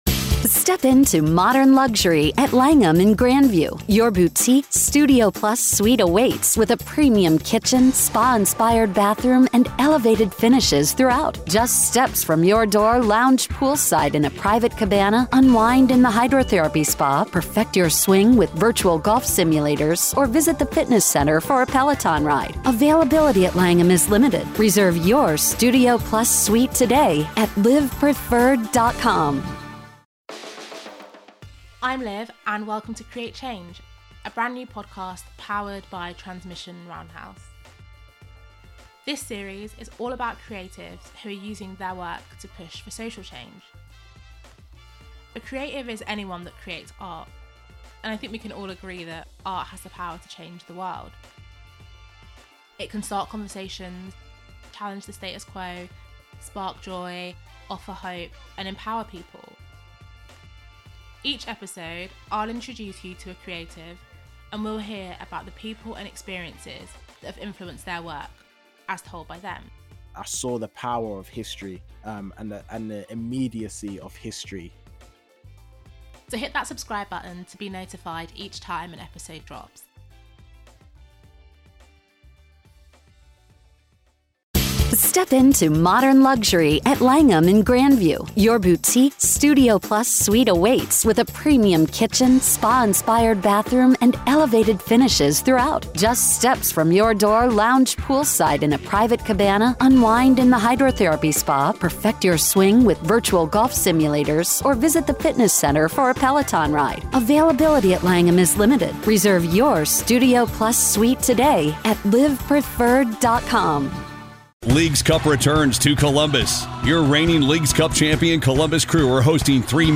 An immersive storytelling podcast, sharing the experiences and influences behind creatives pushing for social change through their work.